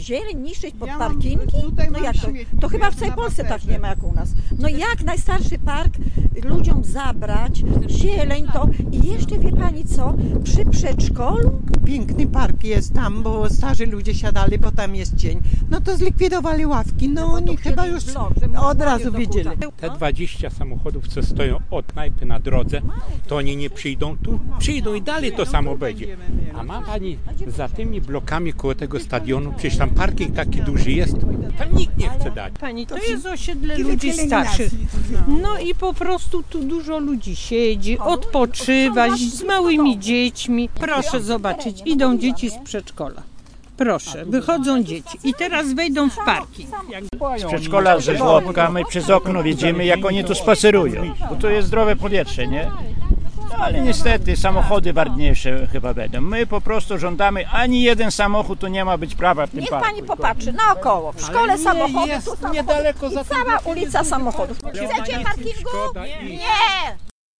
Posłuchaj, co mówią mieszkańcy:
0208_parking_mieszkancy.mp3